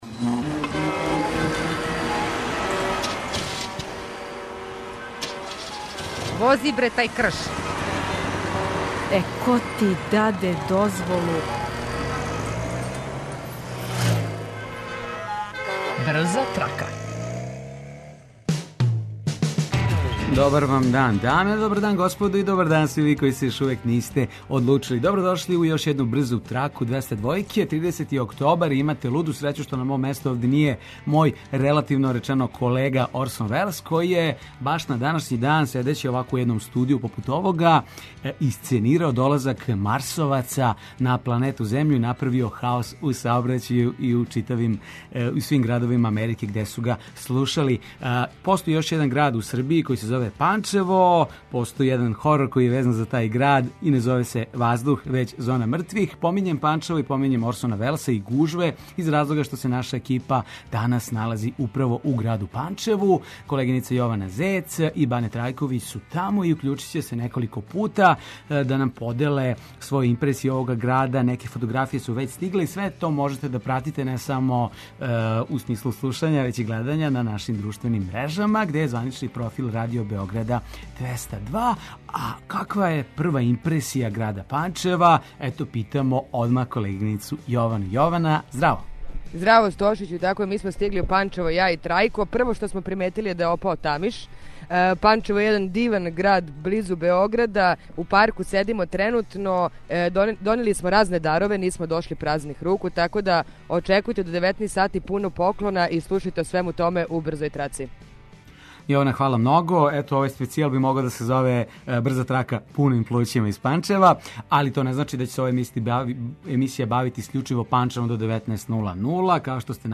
Водимо вас на разне фестивале, представе, концерте...Слушаоци репортери јављају новости из свог краја, па нам се јавите и ви.
Проверићемо зашто се каже да су Панчевци најлошији возачи и да ли је то уопште тачно. Прошетаћемо централним градским улицама и дружити се са становницима овог града надомак Београда.